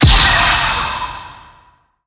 Add ghost sounds.
appear.LN50.pc.snd.wav